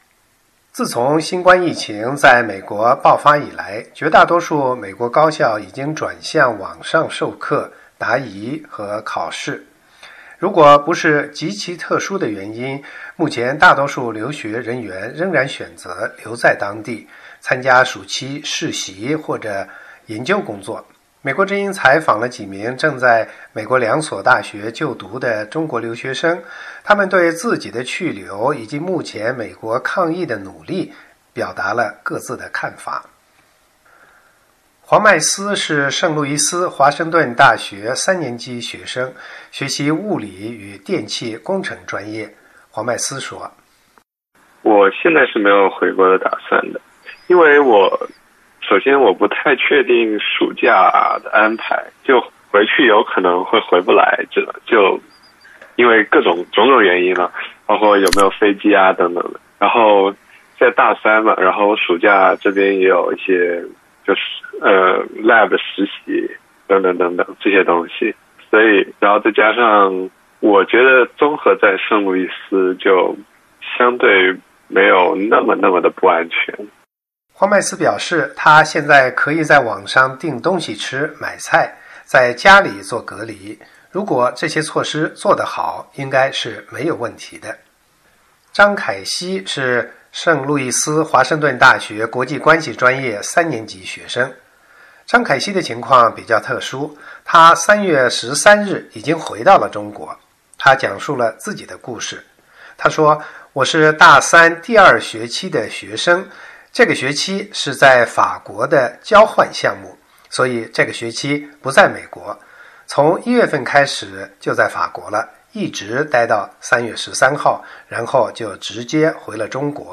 美国之音采访了几名正在美国两所大学就读的中国留学生，他们对自己的去留，以及目前美国抗疫的努力表达了各自的看法。